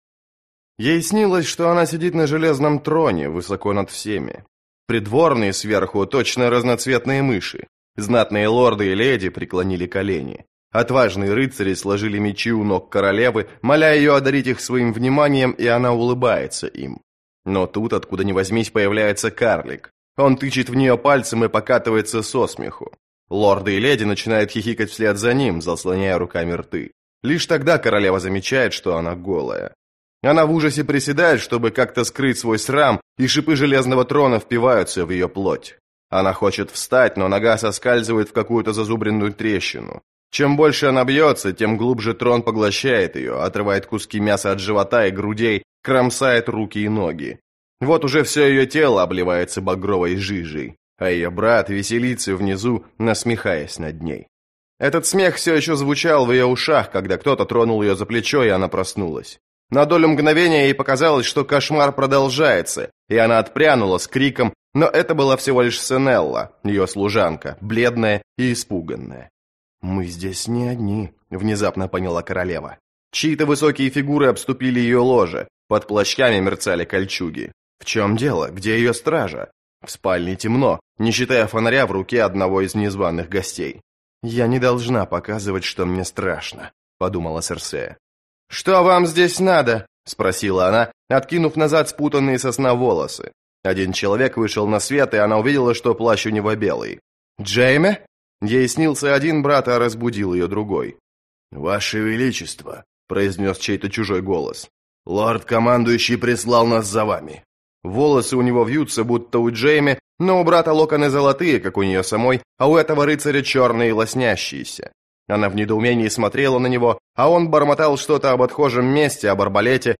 Аудиокнига Пир стервятников - купить, скачать и слушать онлайн | КнигоПоиск